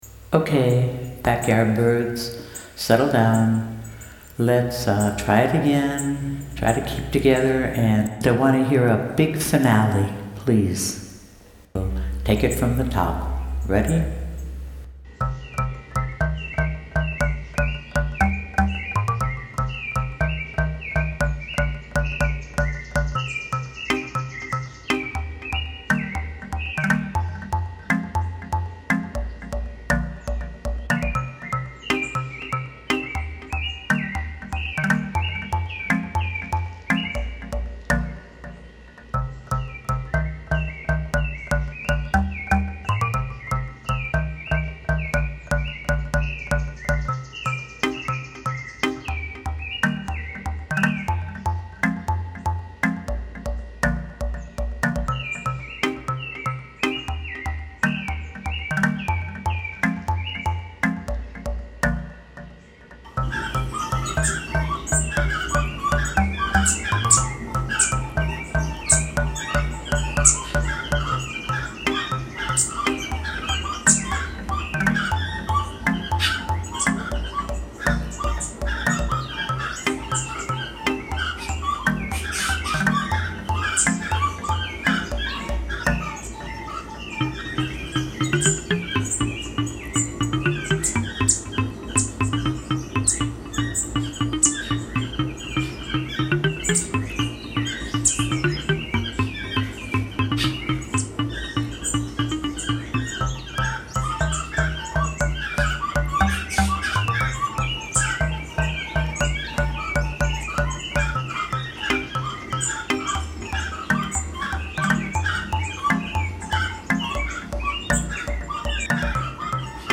My backard birds, featuring the soloist and National Bird, (not the prettiest but that doesn’t matter, she sings like an angel  *Center photos.) She is The Yiguirro (a brown thrush) In the chorus we have, Toucans (two types) The Chacalacas, (bottom left..the size of a small turkey) and the Cuckcoo sounding Chirincocos (Bottom right).
I appologize if this is not perfect..they seem to be afraid of the conductor so they are pretty much on their own..
Haha, the sorely-tried patience in the conductor’s voice!
Ha very energetic bird songs,.love it! 😀
final-bird-symphony.mp3